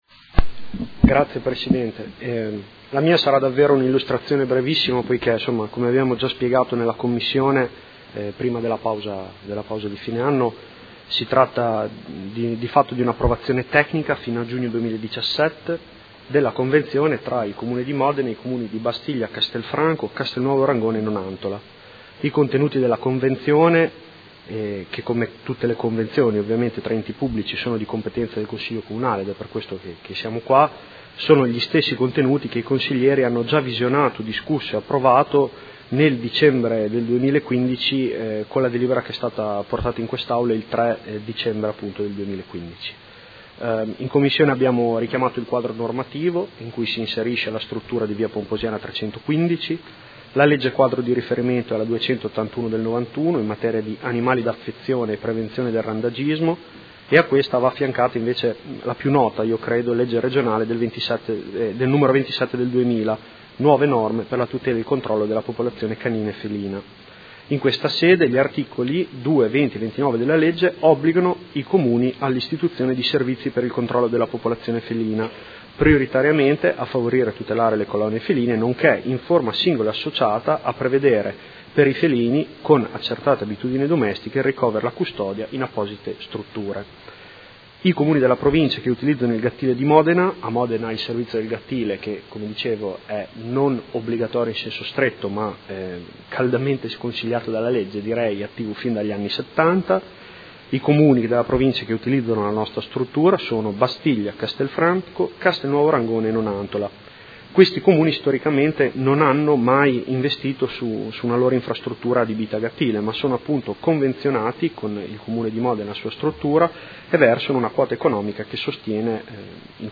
Giulio Guerzoni — Sito Audio Consiglio Comunale